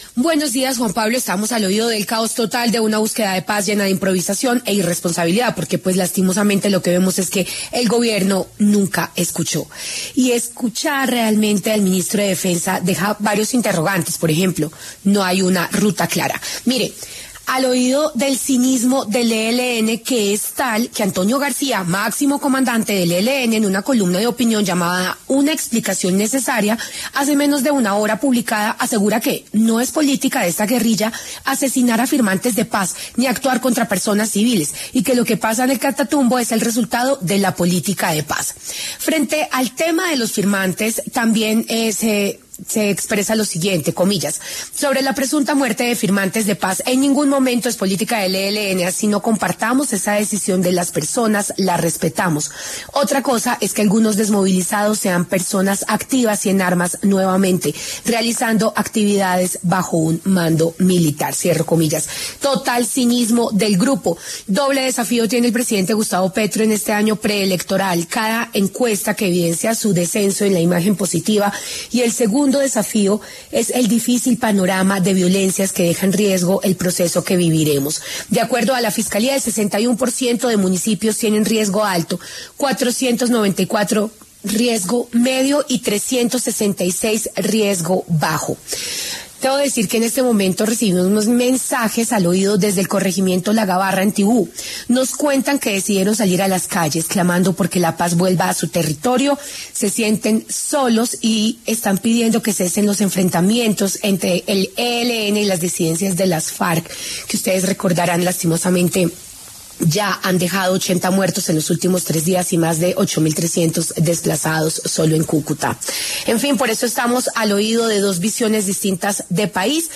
En diálogo con Al Oído, la senadora Paloma Valencia expresó: “la Paz Total del Gobierno Petro es un verdadero fracaso total (…) Más de 5.000 desplazados en el Catatumbo por las acciones criminales del ELN y las disidencias de las Farc. Colombia sufre las consecuencias de un mal gobierno que permitió el fortalecimiento de los delincuentes en los municipios del país”.